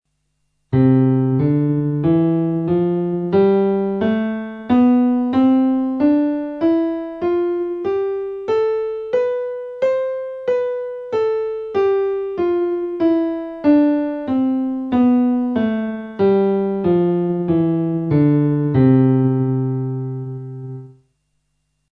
Digitación de escalas mayores en tonalidades sencillas Bajar audio MP3: Escala_Do_MD_1_oct.mp3 .
Escala_Do_MD_2_oct.mp3